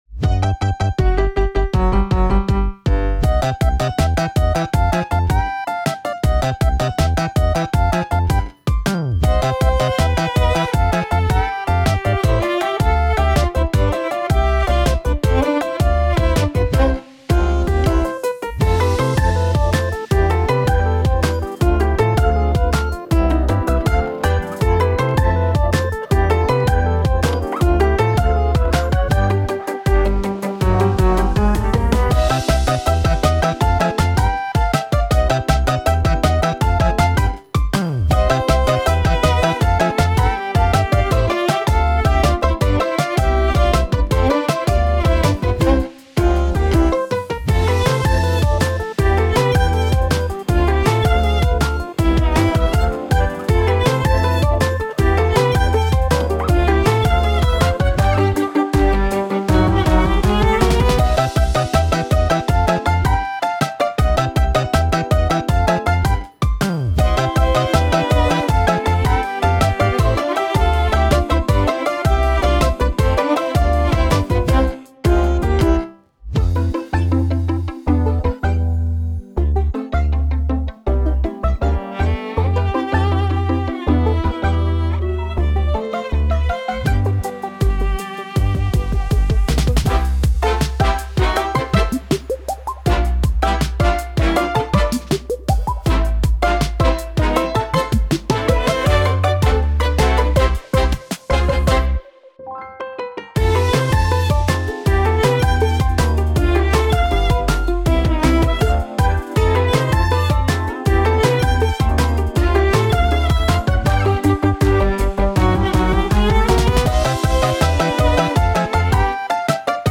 Love how bouncy this one is